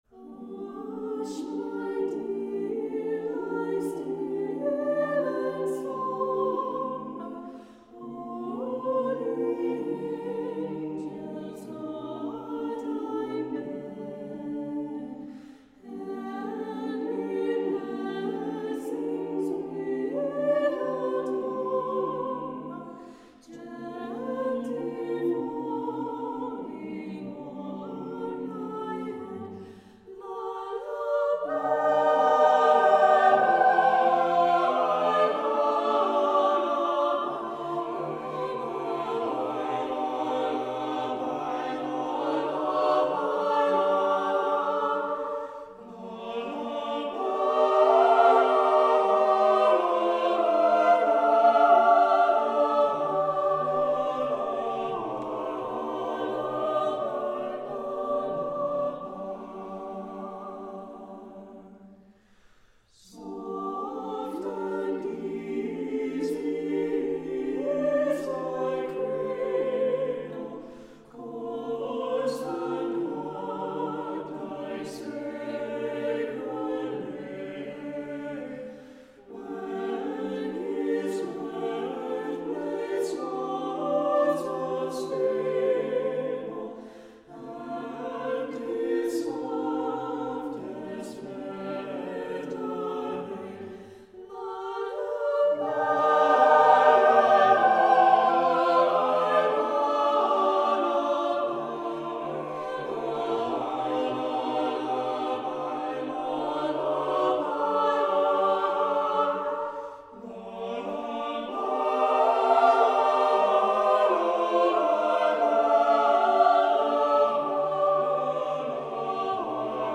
Voicing: SATB a cappella